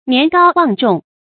年高望重 注音： ㄋㄧㄢˊ ㄍㄠ ㄨㄤˋ ㄓㄨㄙˋ 讀音讀法： 意思解釋： 年紀大，聲望高。